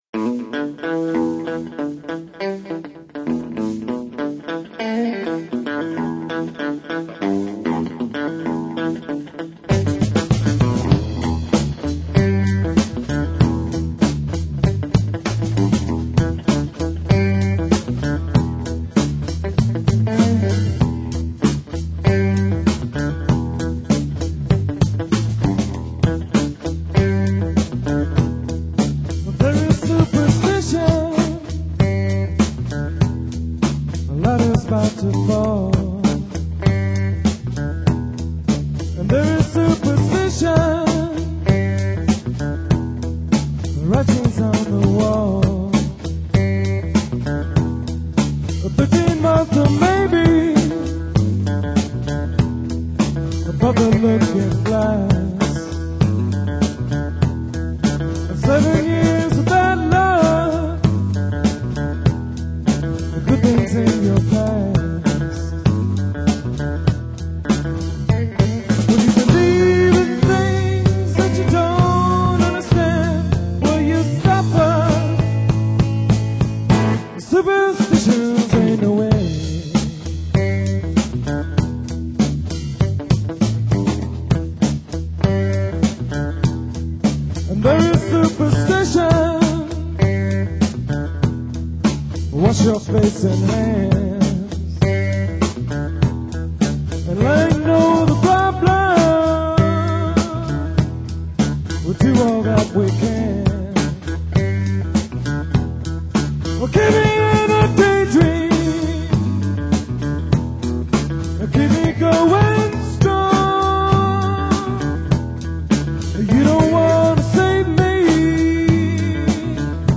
drums
guitar
bass